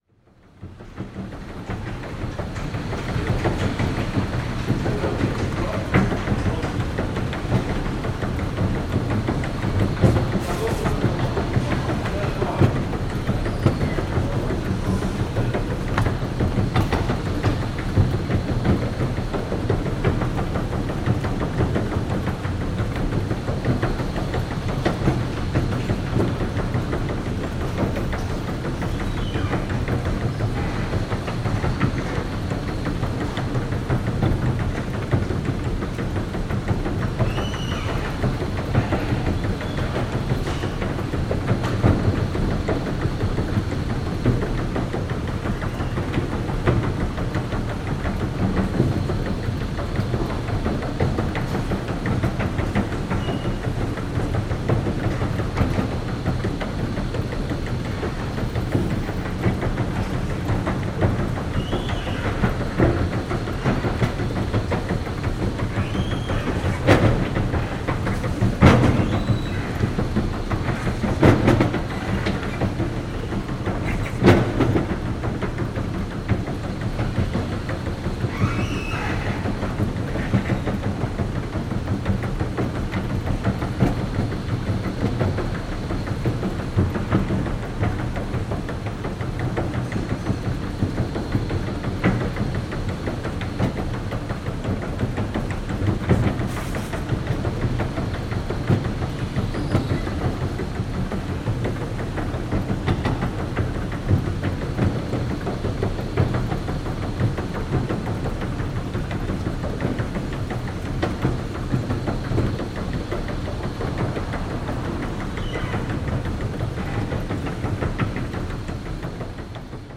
движение звуки скачать, слушать онлайн ✔в хорошем качестве